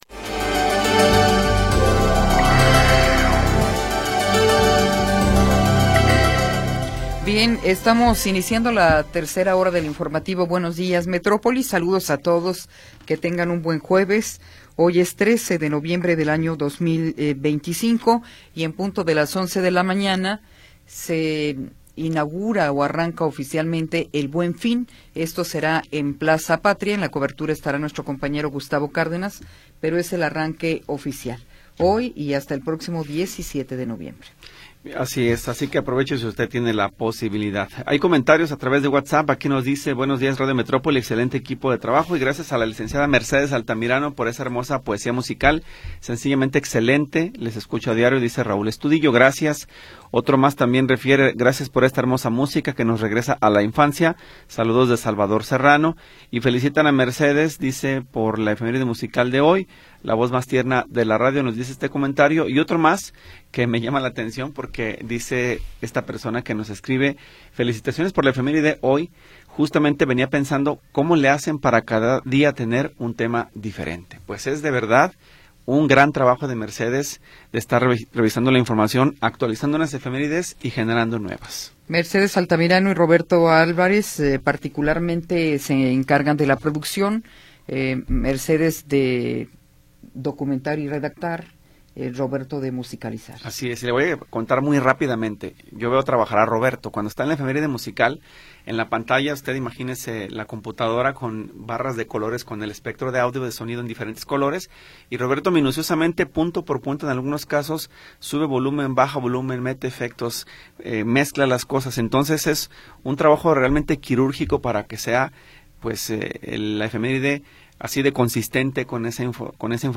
Información oportuna y entrevistas de interés
Tercera hora del programa transmitido el 13 de Noviembre de 2025.